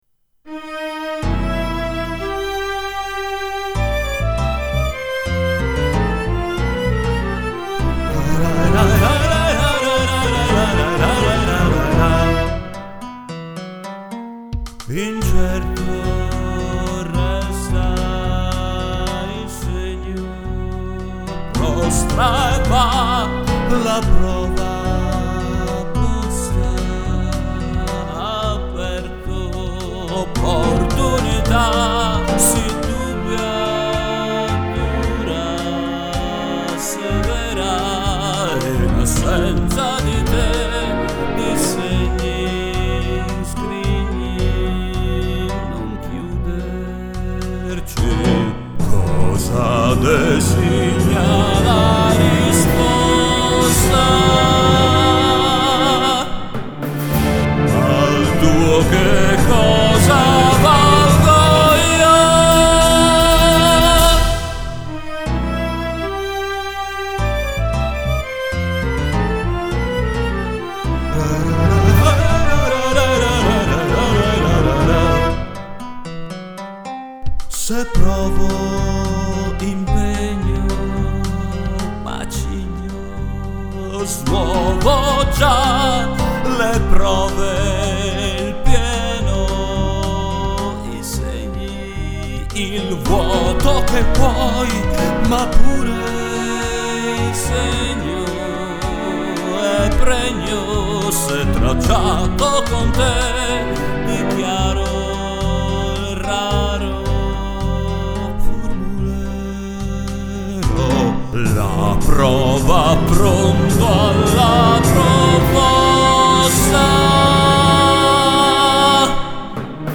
PROVINI di canzoni (registrazioni casalinghe)